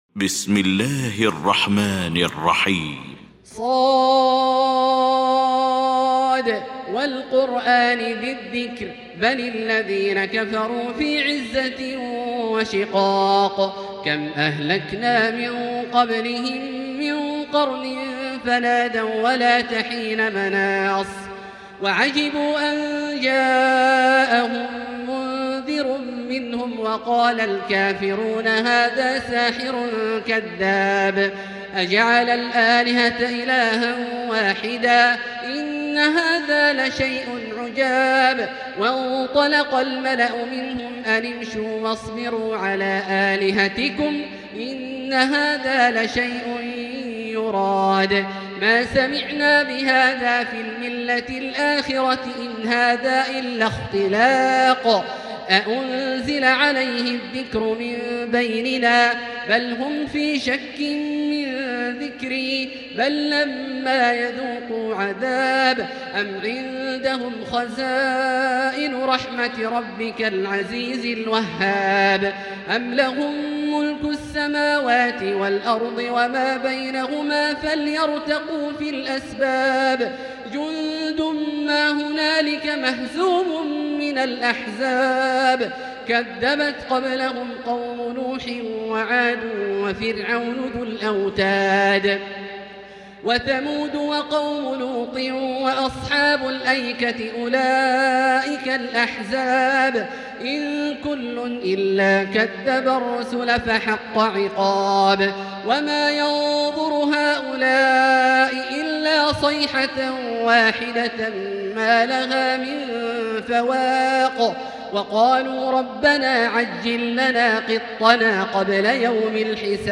المكان: المسجد الحرام الشيخ: فضيلة الشيخ عبدالله الجهني فضيلة الشيخ عبدالله الجهني ص